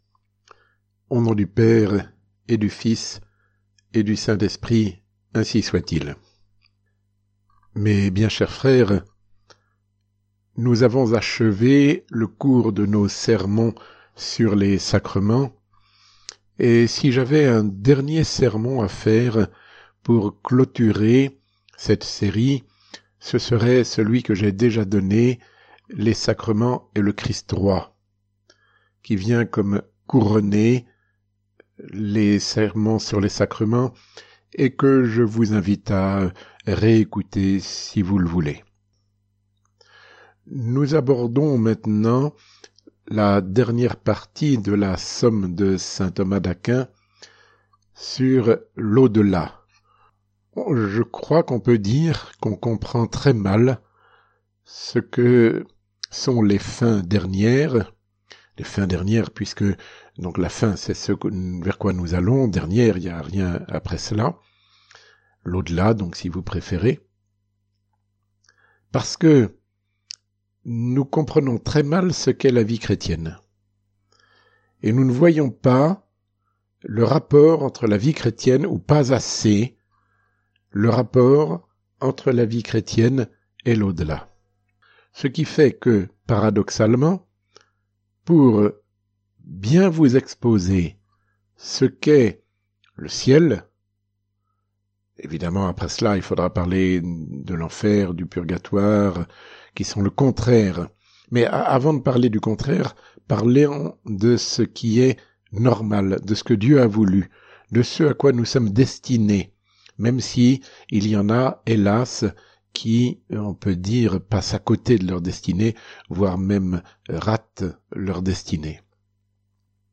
Résumé du sermon